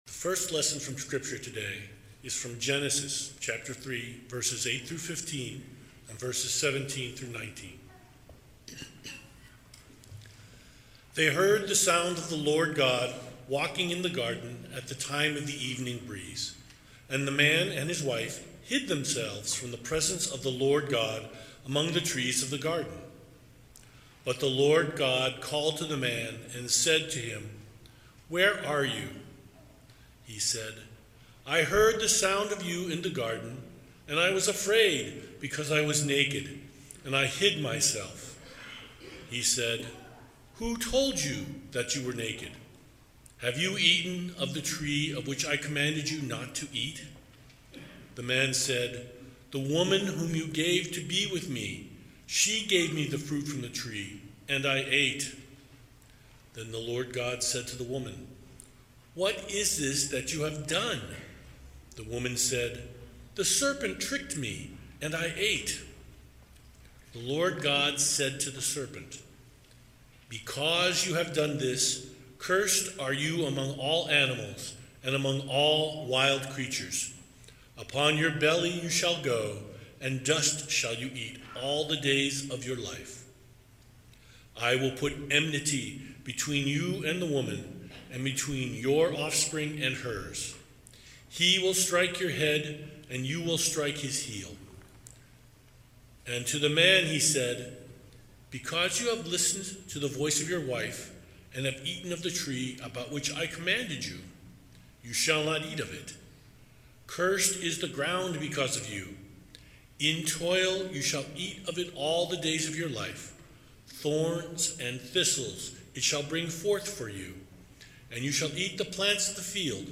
2025 Current Sermon Lessons & Carols Christmas Musical Celebration What Child IS This?